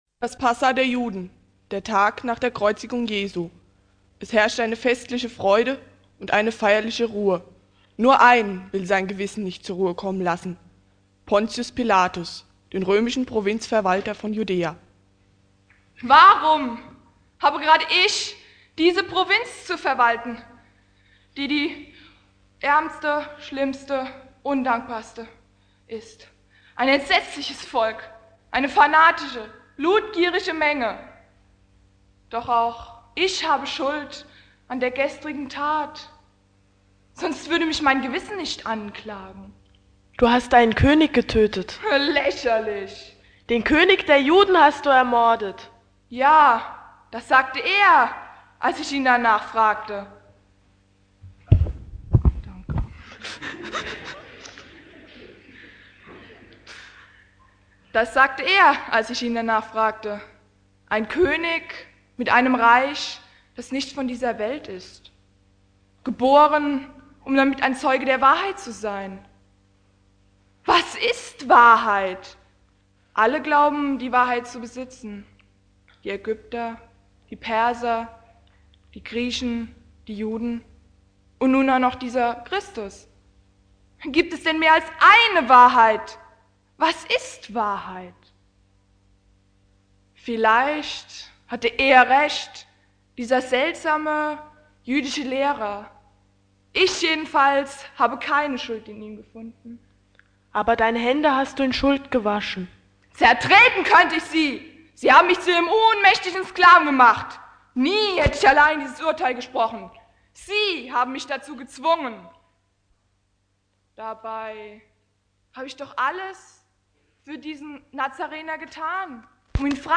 Predigt
Karfreitag
Anspiel zur Predigt "Pilatus" Predigtreihe